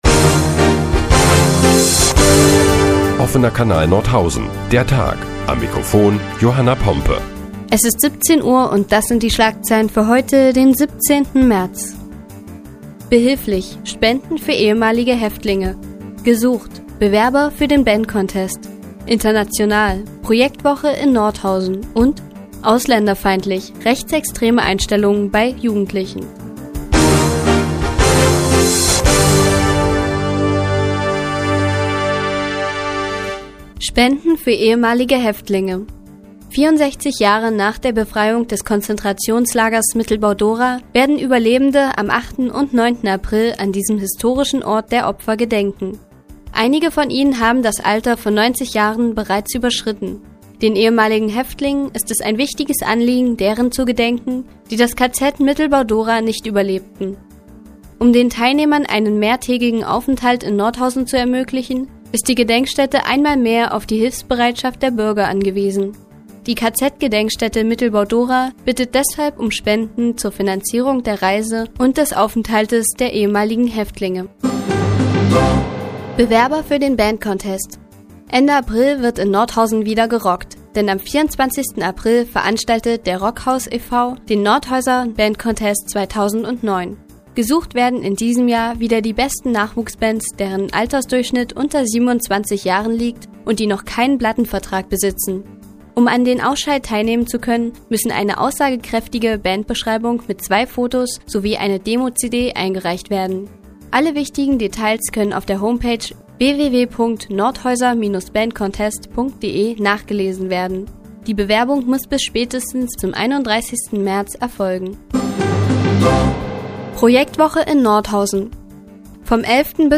Die tägliche Nachrichtensendung des OKN ist nun auch in der nnz zu hören. Heute geht es unter anderem die internationale Projektwoche an der FH Nordhausen und die zunehmende Ausländerfeindlichkeit bei Jugendlichen.